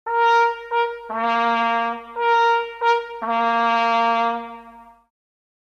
Jagdhornbläser
Wenn auch die Jagdhörner in der Zahl der zur Verfügung stehenden Töne begrenzt sind, so vermitteln sie doch mit ihren einfachen Melodienfolgen beim Zuhörer einen unvergleichlichen naturverbundenen Eindruck.